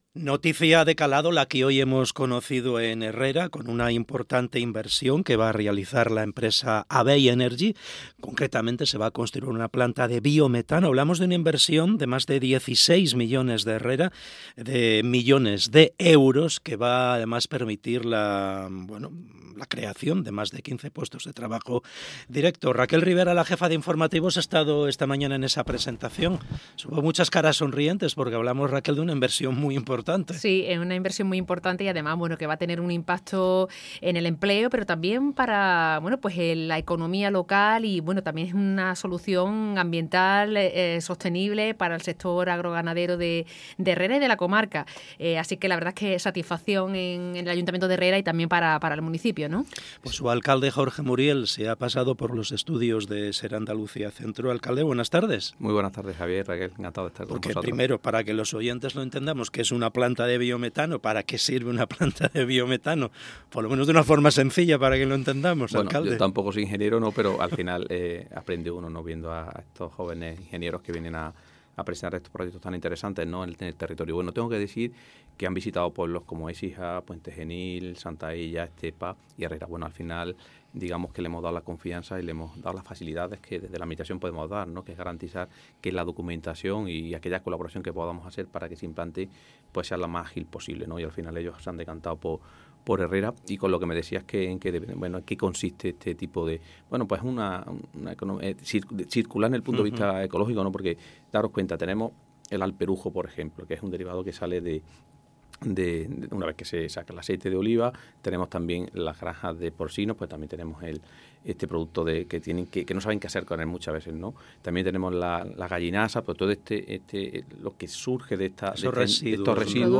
ENTREVISTA | Jorge Muriel, alcalde de Herrera